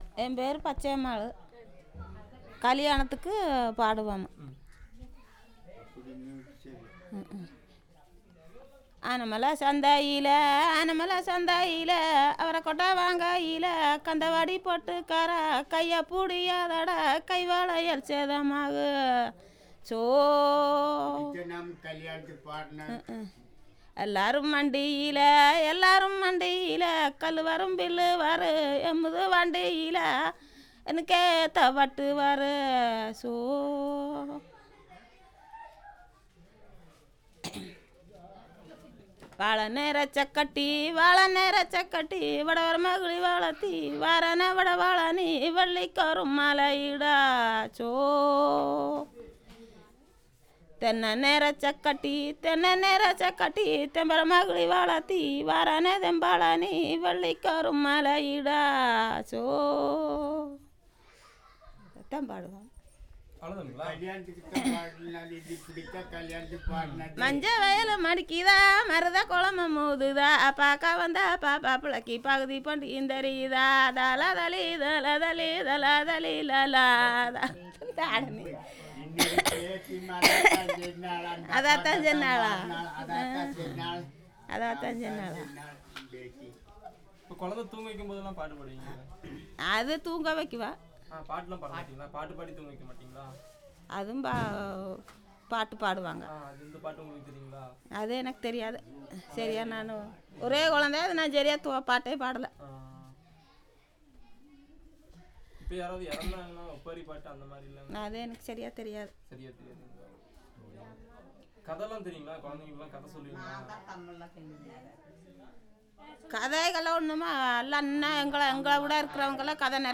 Performance of traditional song